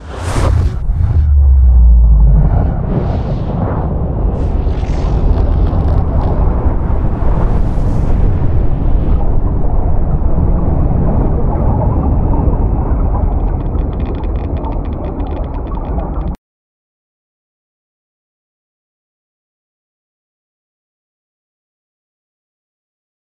File:AuroraExplosion.ogg